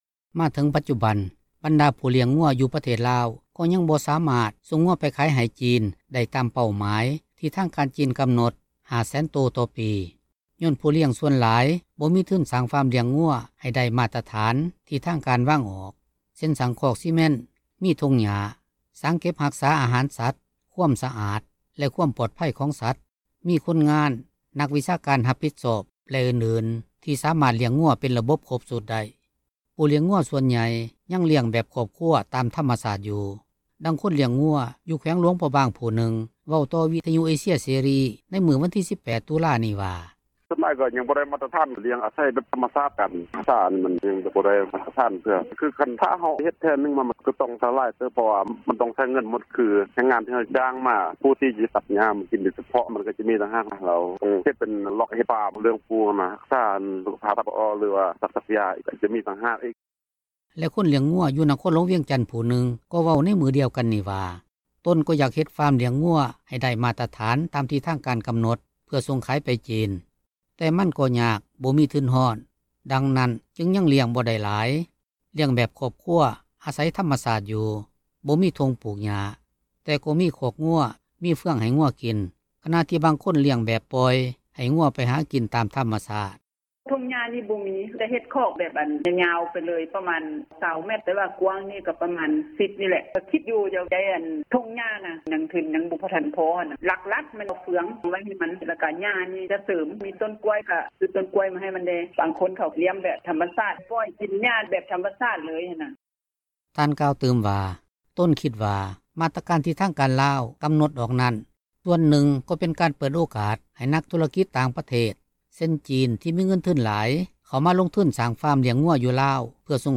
ນັກຂ່າວ ພົລເມືອງ
ດັ່ງຄົນລ້ຽງງົວ ຢູ່ແຂວງຫລວງພຣະບາງ ຜູ້ນຶ່ງເວົ້າຕໍ່ວິທຍຸ ເອເຊັຽເສຣີ ໃນມື້ວັນທີ 18 ຕຸລານີ້ວ່າ: